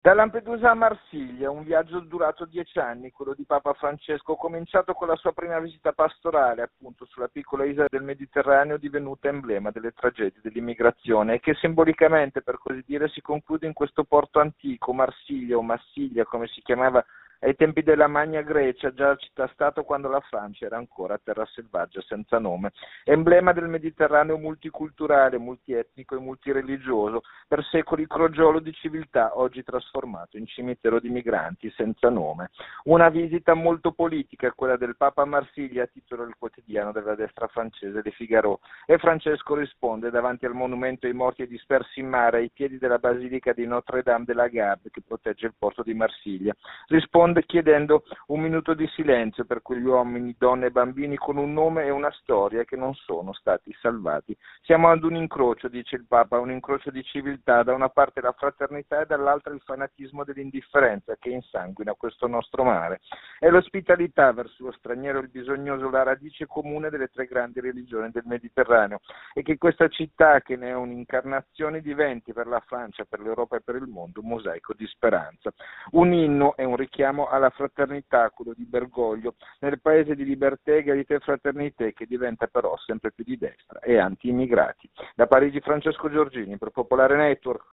Il servizio da Parigi